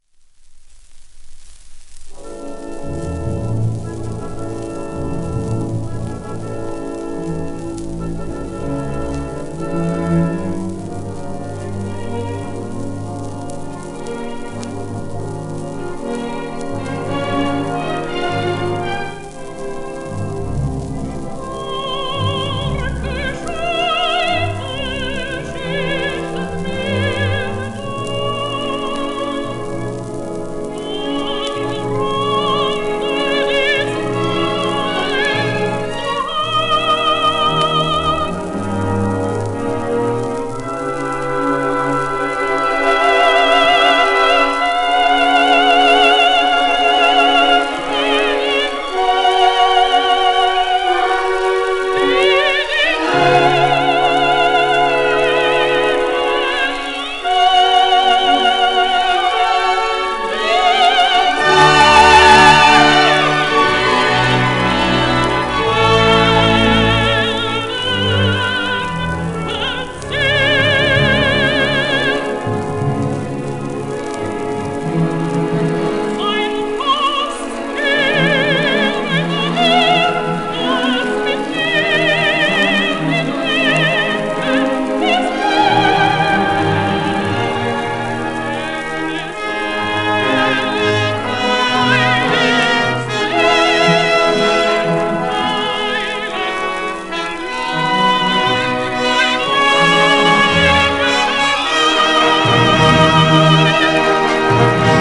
盤質B+/A- *一部にやや溝荒あり、小キズ、レーベル小キズ